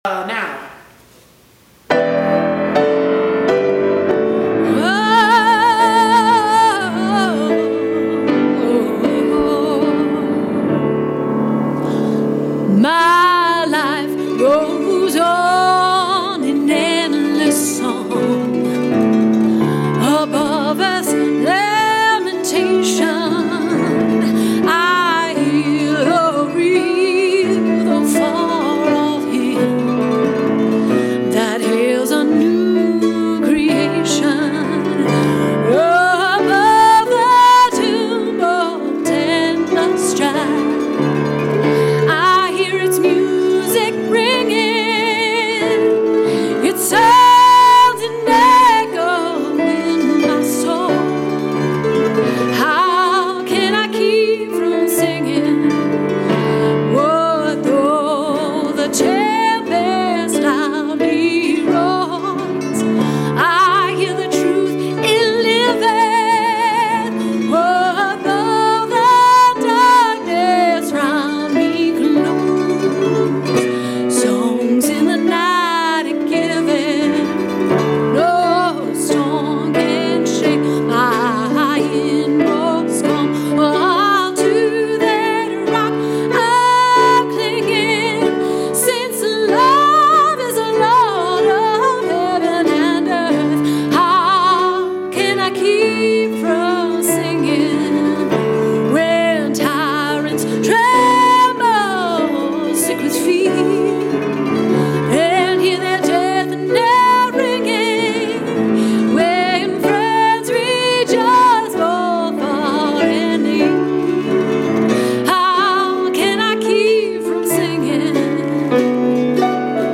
Easter Musical Performance